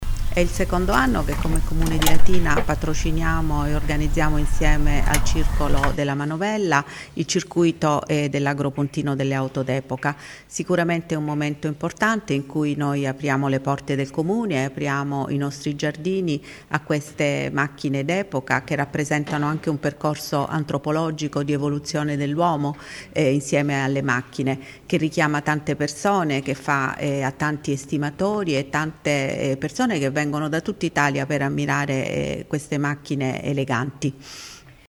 sindaco_circuito.mp3